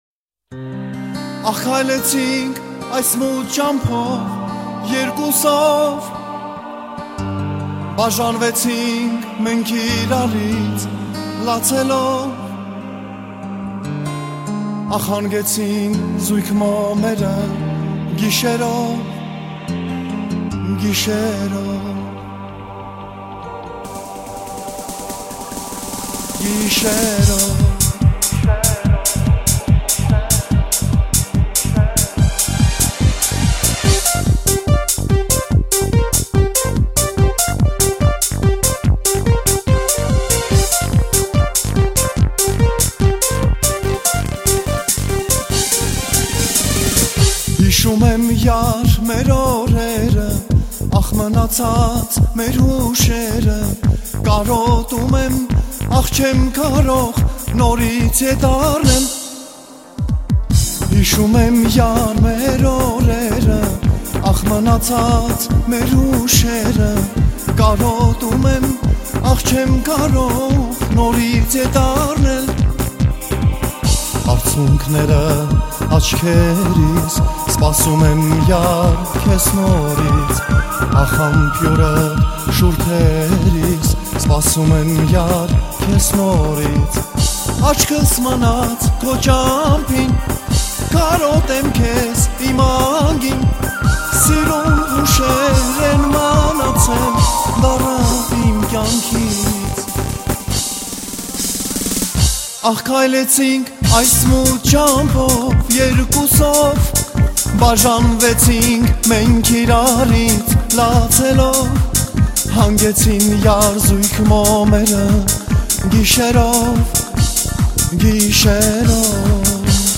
ռաբիզ